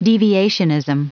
Prononciation du mot deviationism en anglais (fichier audio)
Prononciation du mot : deviationism
deviationism.wav